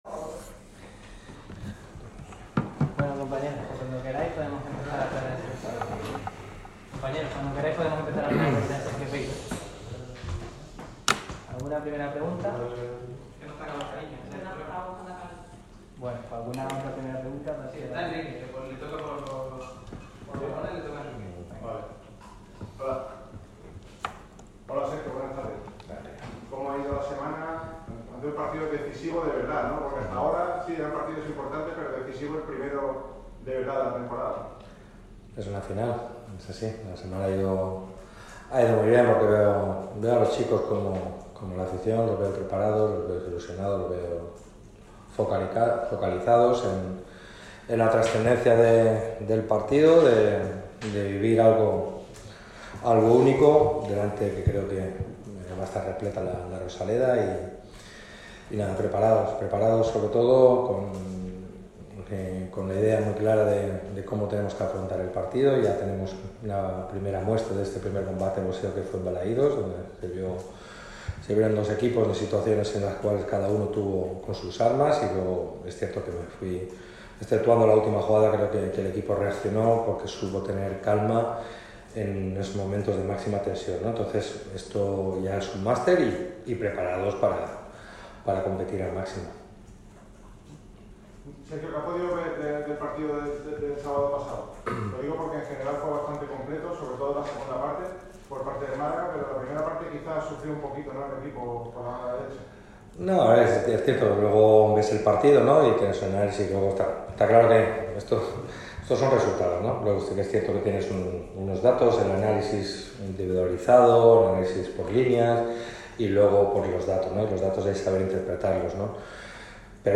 El entrenador del Málaga CF, Sergio Pellicer, ha comparecido ante los medios de comunicación con motivo de la rueda de prensa previa al choque frente al Celta